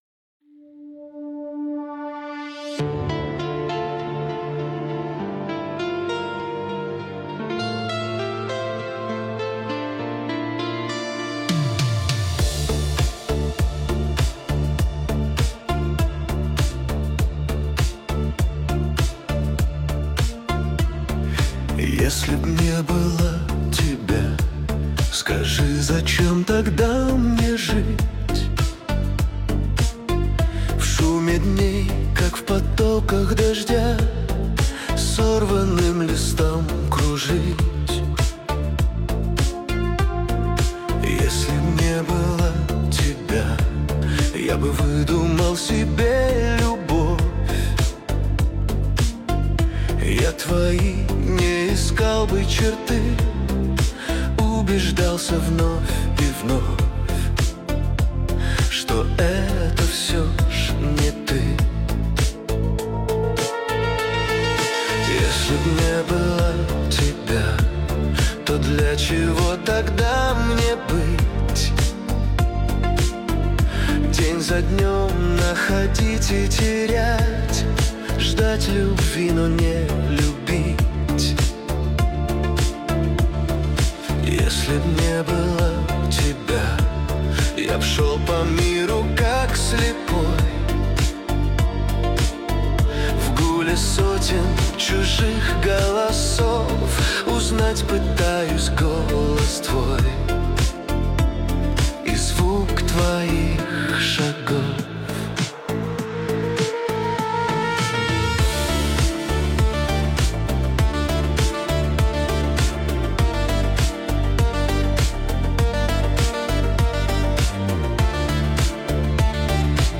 Качество: 320 kbps, stereo
Нейросеть Песни 2025